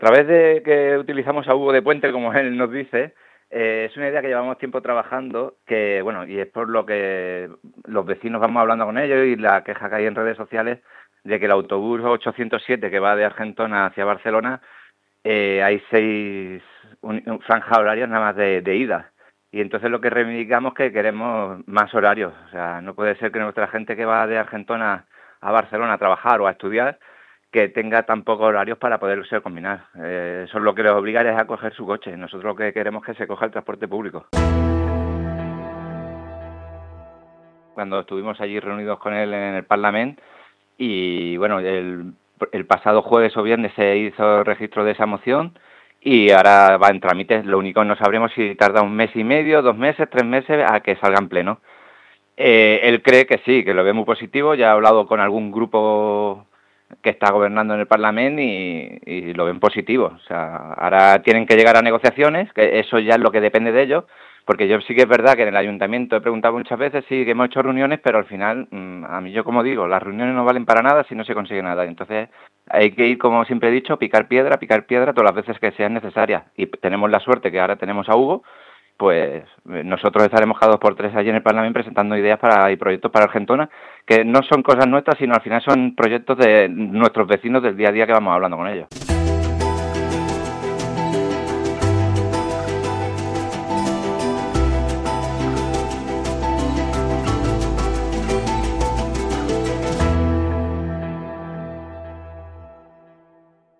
El regidor popular Fran Fragoso explica que la proposta de millora es debatrà al Parlament.